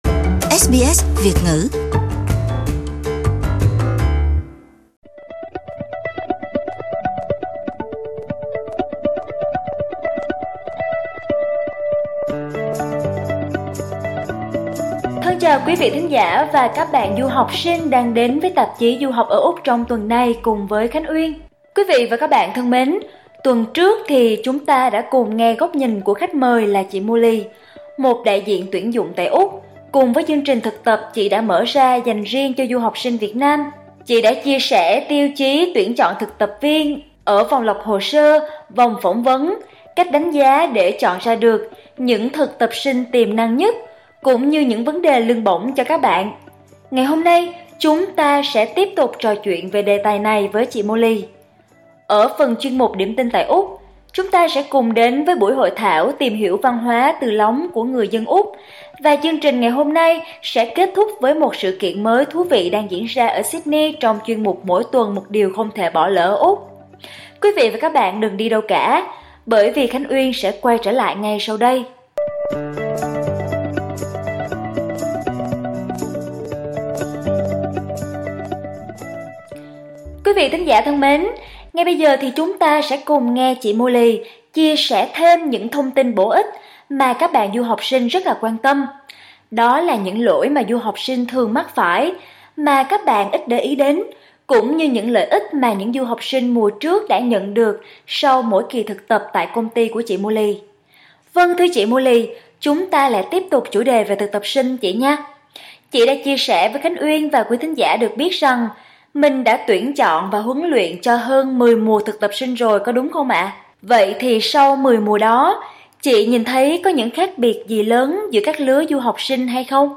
SBS Việt ngữ phỏng vấn